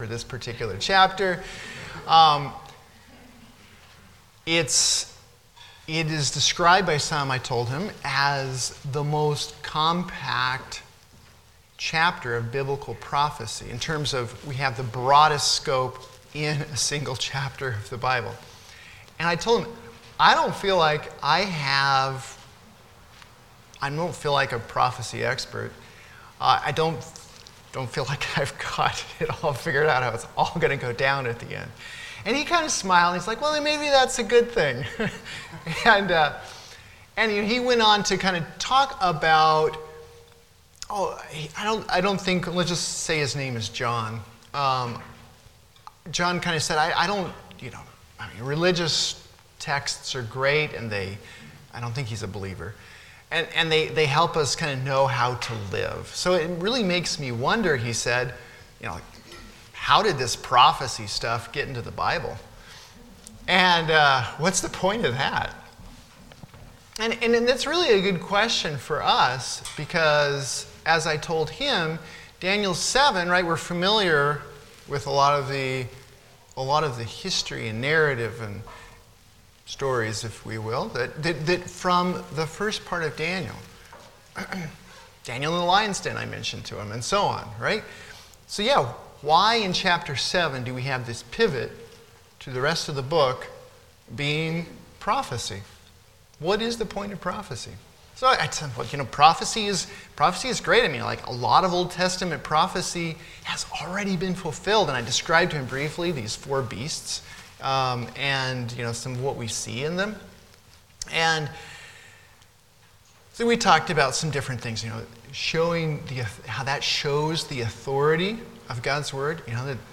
Passage: Daniel 7 Service Type: Sunday School